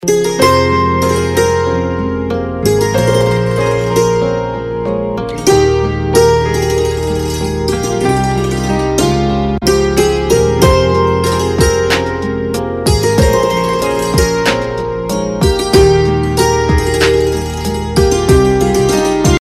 Instrumental MP3 Ringtones > New Bollywood